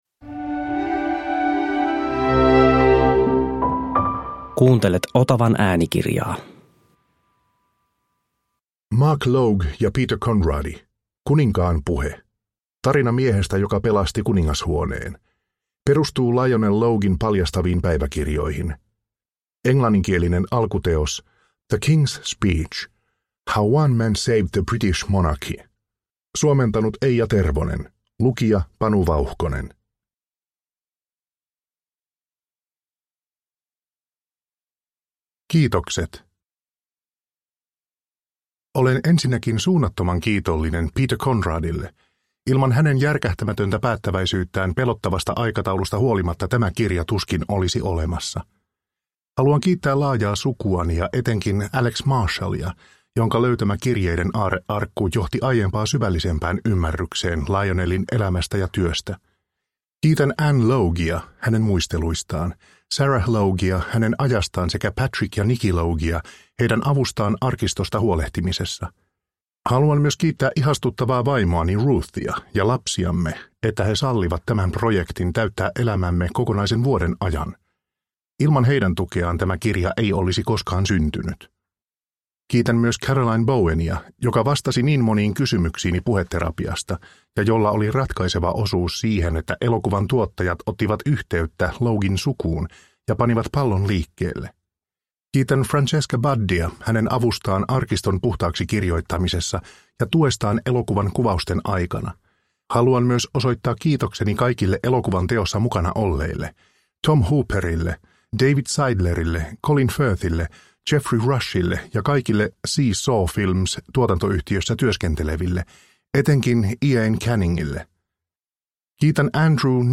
Kuninkaan puhe – Ljudbok – Laddas ner